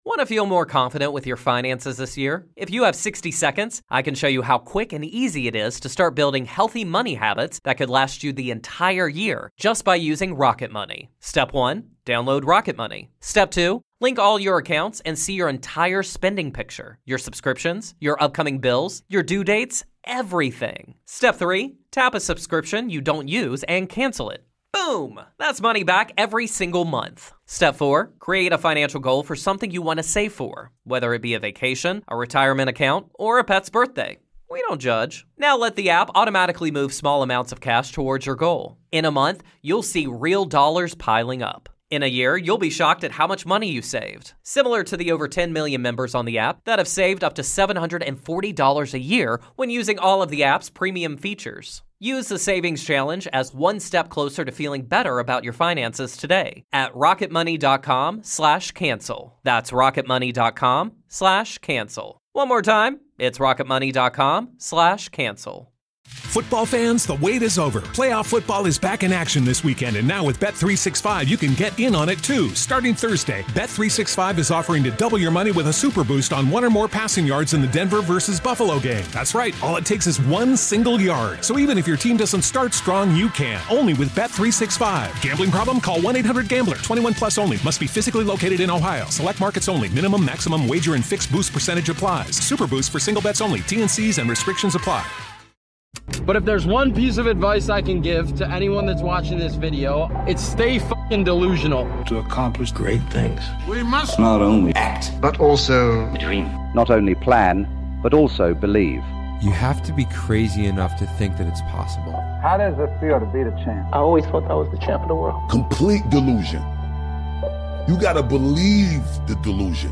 This 32-minute compilation brings together the wisdom, stories & mindset of legends — Conor McGregor, Mike Tyson, Jim Rohn, Brian Tracy, Bob Proctor, and Napoleon Hill & many more — voices that have inspired millions to dream bigger and achieve more.